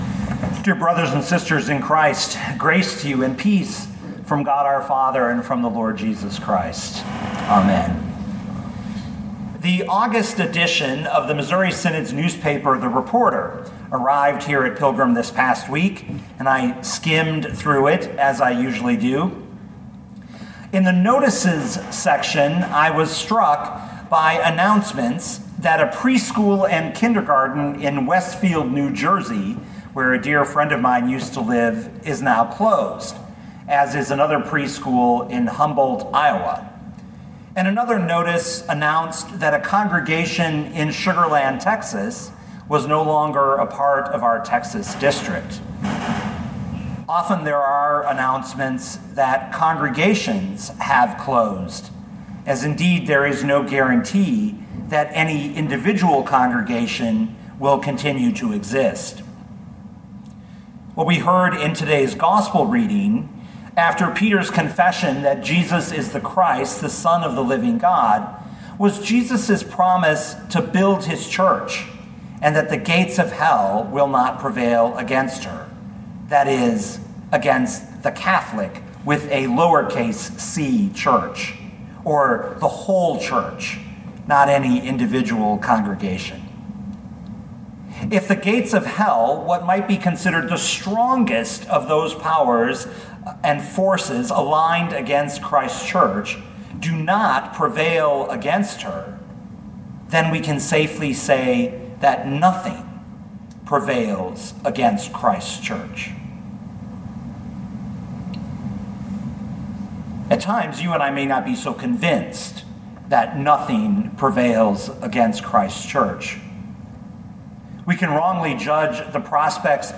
2020 Matthew 16:13-20 Listen to the sermon with the player below, or, download the audio.